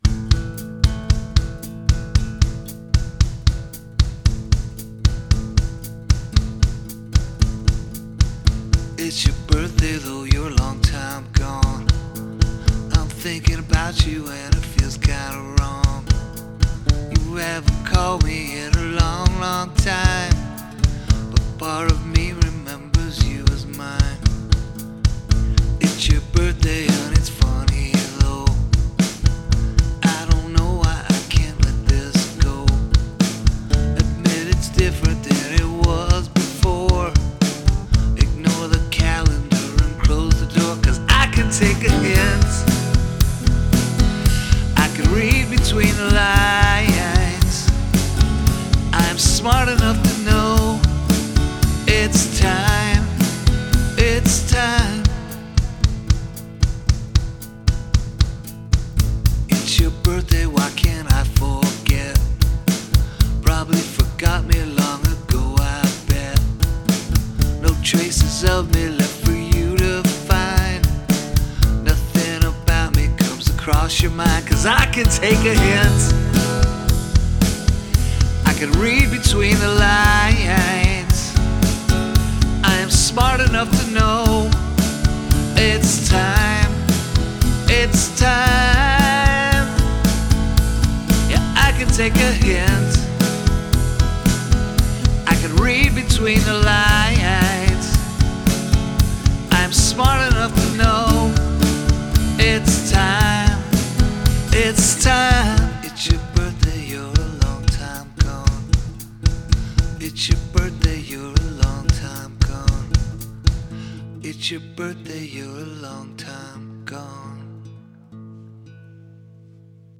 A relatable story, with good strong emotions and delivery.